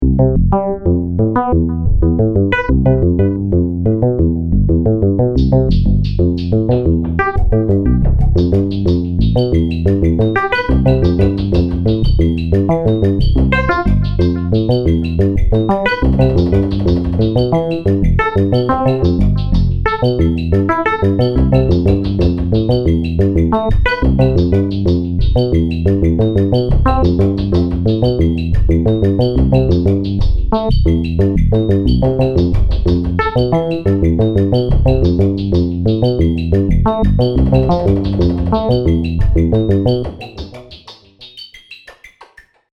Intelligent dance music (IDM)
melodía repetitivo sintetizador